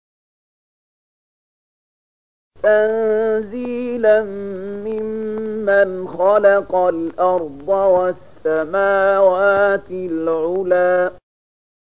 020004 Surat Thaahaa ayat 4 dengan bacaan murattal ayat oleh Syaikh Mahmud Khalilil Hushariy: